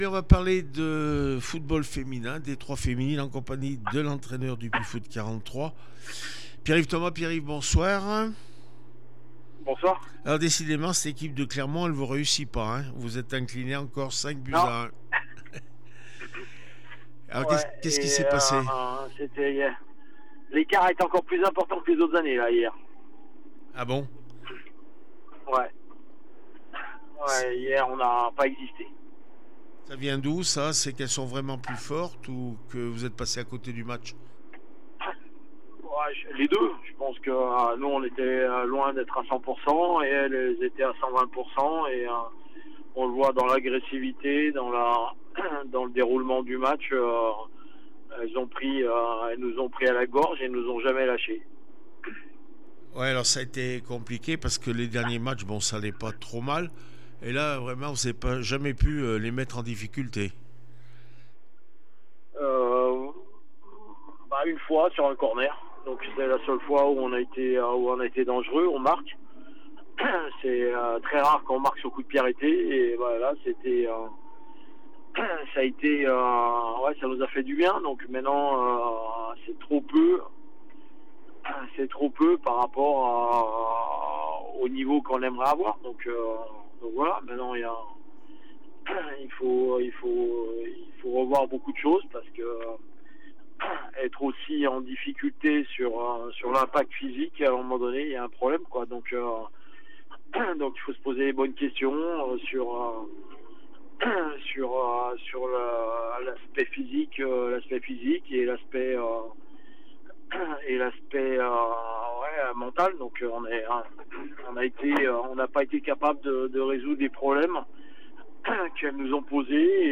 17 novembre 2025   1 - Sport, 1 - Vos interviews